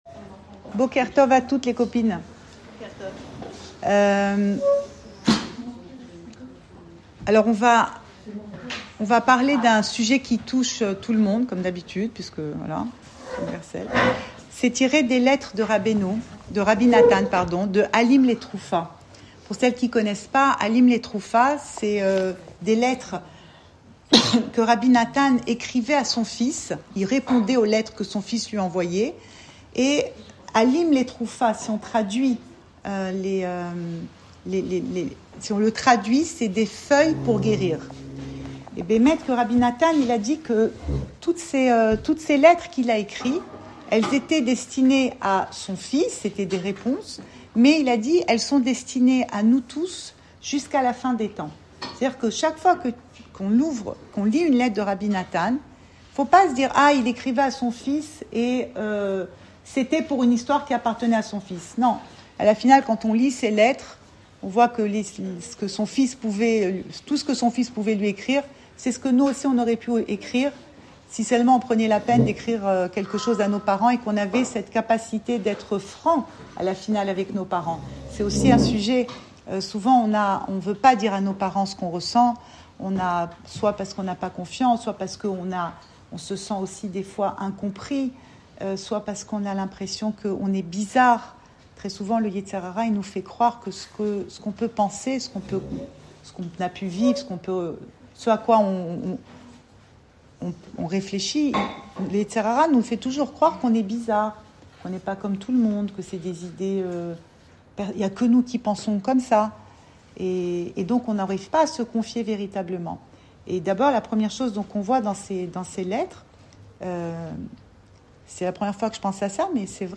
Cours audio Le coin des femmes Le fil de l'info Pensée Breslev - 28 janvier 2026 28 janvier 2026 Une pincée de sel. Enregistré à Tel Aviv